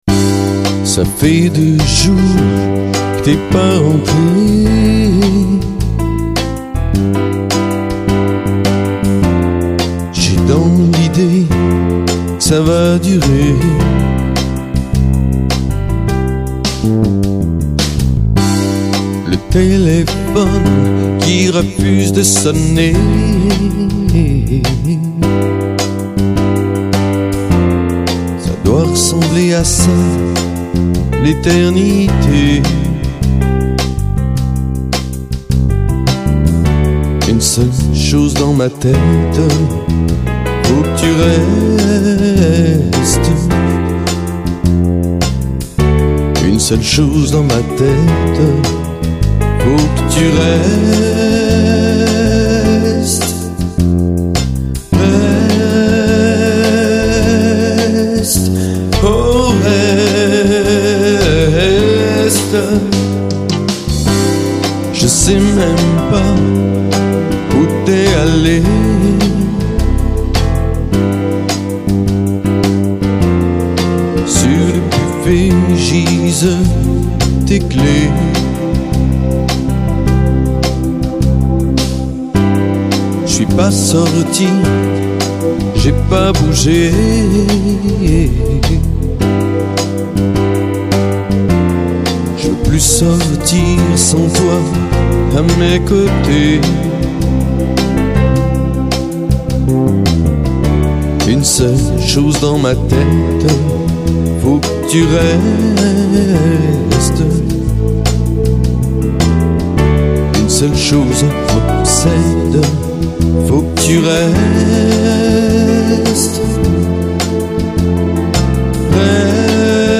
Vos Compos Chanson française
Option soul
mais cest bien realisé, bien mixé
Eighties en général
déjà ta voix, elle me fait penser à celle de michel jonaz, un peu crooner, avec le vibrato, et le piano derrière, l'instru pareil trop vieillote (avec la guitare, le piano cheap)
Tu as de bons arrangements qui tiennent la route. bémol en ce qui concerne la voix: tu as une couleur intéressante mais l'interprètation ne met pas le texte en valeur : point faible (à mon très humble avis) c'est la tenue de son (problème qui est le miens par ailleurs...)